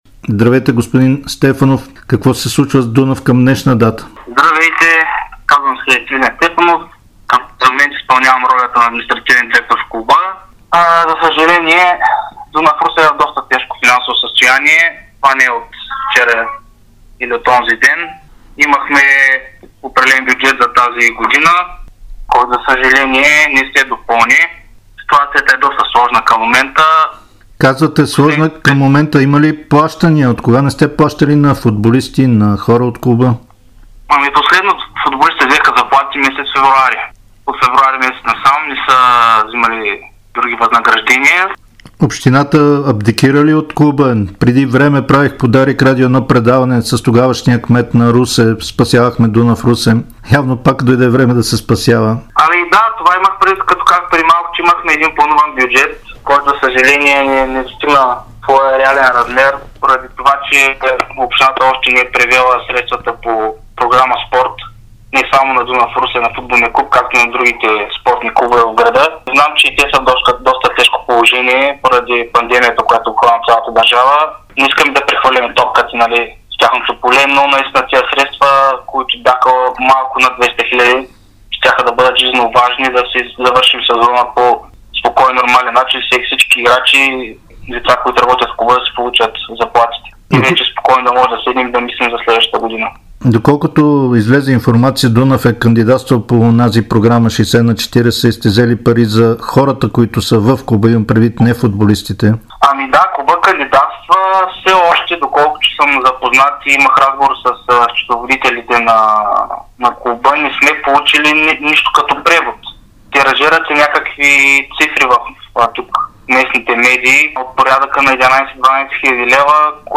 В интервюто той сподели какво е състоянието на отбора, от какво се нуждае Дунав, за да продължи напред и как се справя клуба в тежки финансови условия.